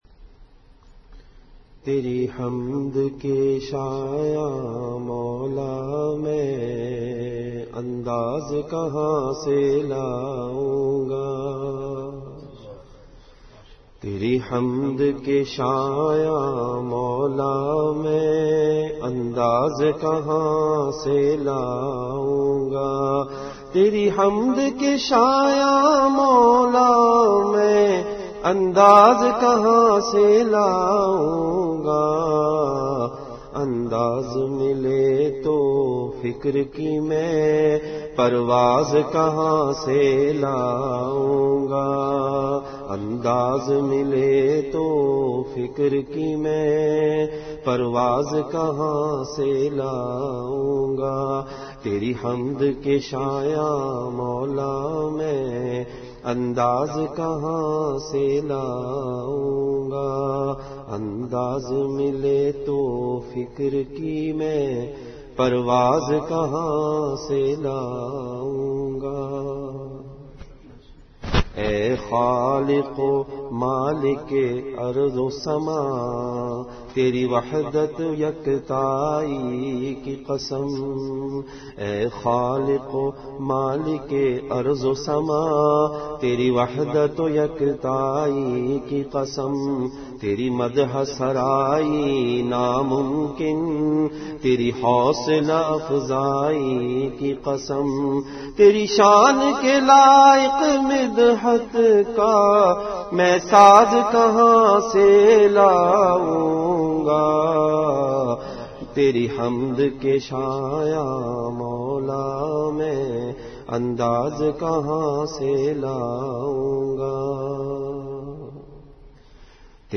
Delivered at Makkah Mukarrama.